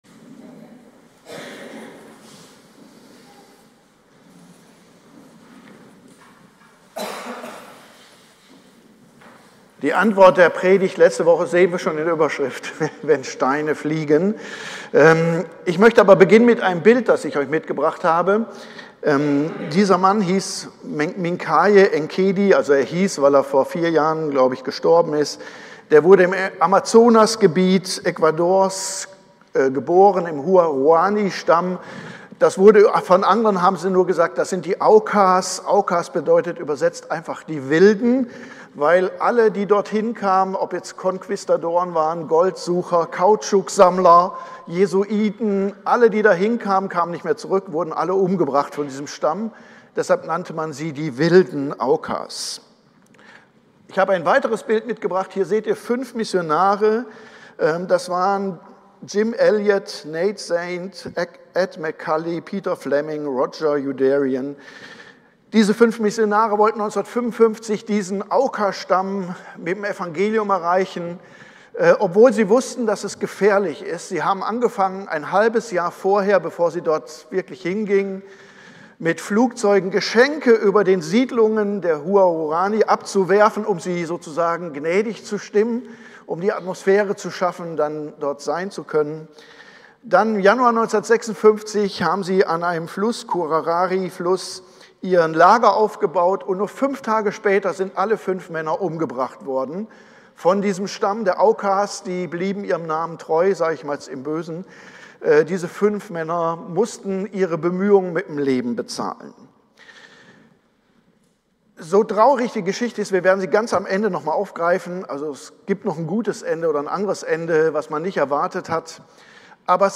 Predigt-am-23.02-online-audio-converter.com_.mp3